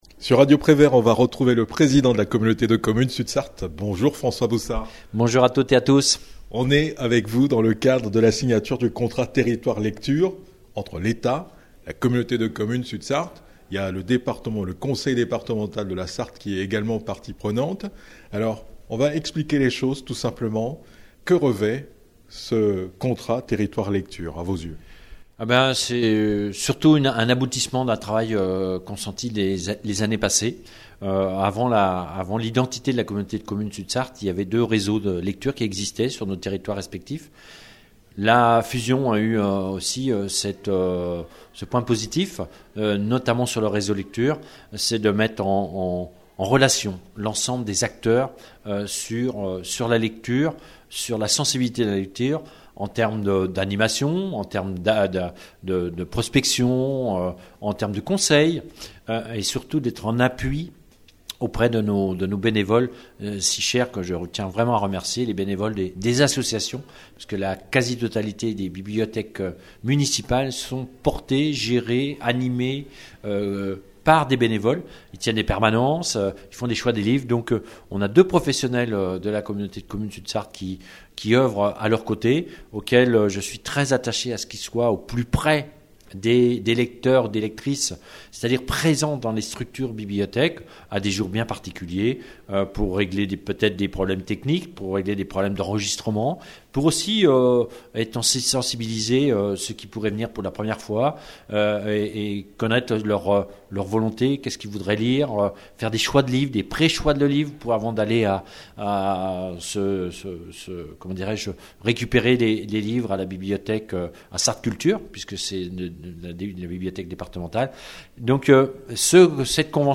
A l'issue de la cérémonie, François Boussard, Président de l'intercommunalité, Véronique Ortet, sous-préfète de l'arrondissement de La Flèche, Véronique Rivron, 1ère vice-présidente du Conseil départemental de la Sarthe, présidente de la Commission vie associative, culture, sport, tourisme et patrimoine, et Eric Martineau, député de la 3e circonscription de la Sarthe ont mis en exergue le rôle fondamental de la lecture dans l'émancipation du citoyen.